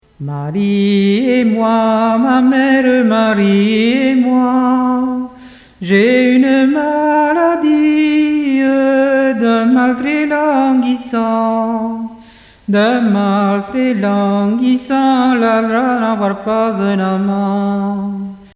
Origine : Tarn
Source : Sant-Amanç mos dos vilatges, cassette audio